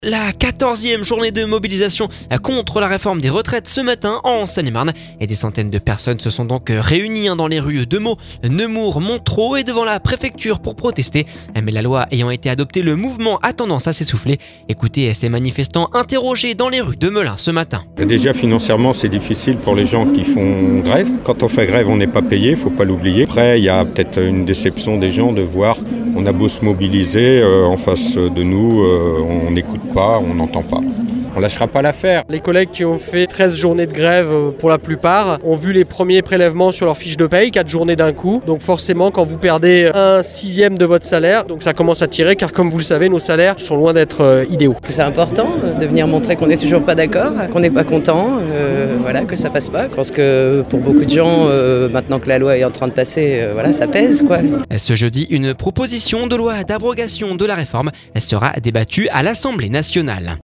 Ecoutez ces manifestants interrogés dans les rues de Melun…